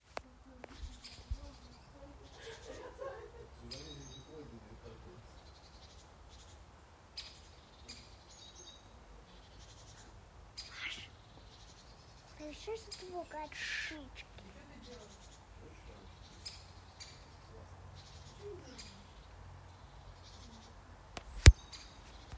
Birds -> Woodpeckers ->
Great Spotted Woodpecker, Dendrocopos major
Administratīvā teritorijaJūrmala
NotesSuga identificēta pēc balss - tā bija atpazīta ar Merlin Bird ID. Ierakstā dzirdams arī Lielās zīlītes balss